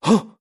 voz nș 0153